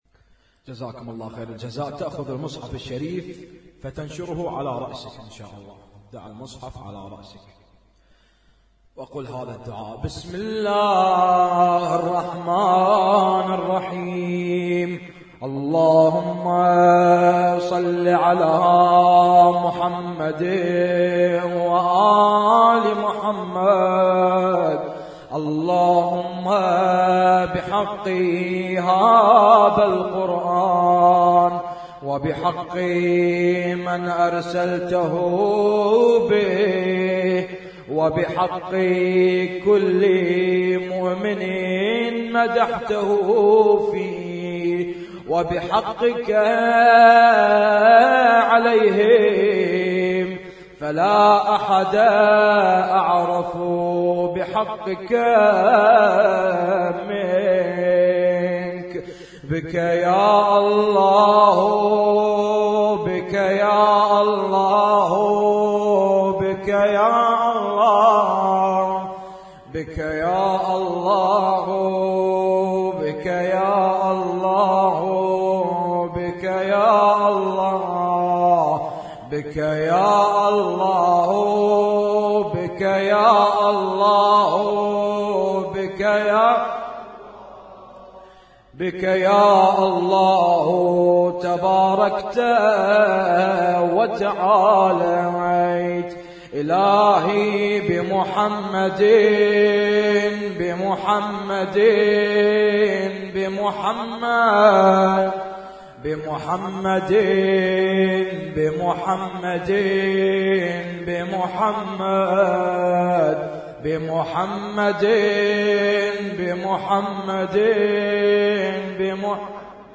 رفع المصاحف - احياء ليلة 22 رمضان 1436
اسم التصنيف: المـكتبة الصــوتيه >> الادعية >> ادعية ليالي القدر